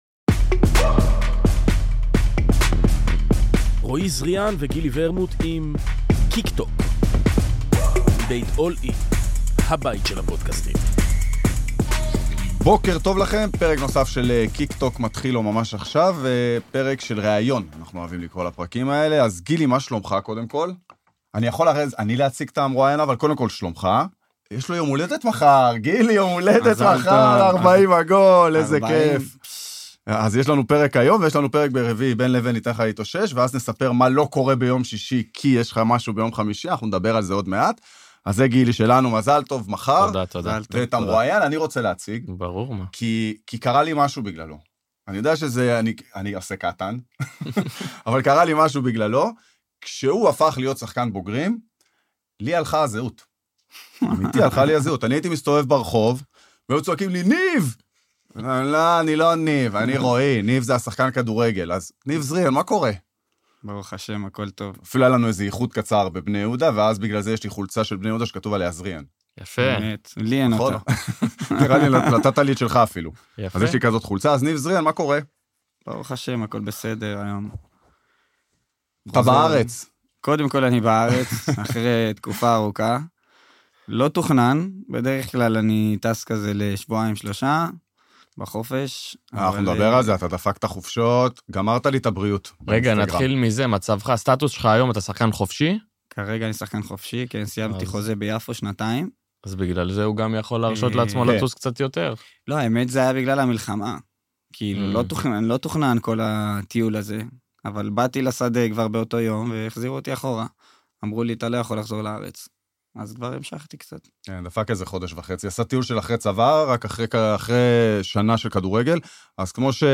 ריאיון